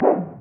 TM88 WeirdPerc.wav